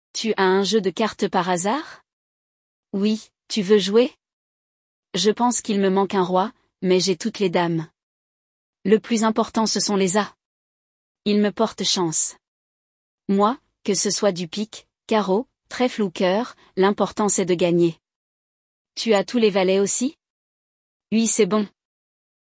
No episódio de hoje, vamos acompanhar um diálogo entre dois amigos: um deles pergunta se o outro tem um baralho, e parece que os dois estão bem interessados no jogo!
LE DIALOGUE